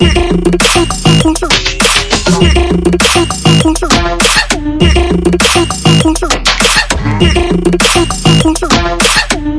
Triphop /舞蹈/拍/嘻哈/毛刺跳/缓拍/寒意
Tag: 寒意 旅行 电子 舞蹈 looppack 样品 毛刺 节奏 节拍 低音 实验 器乐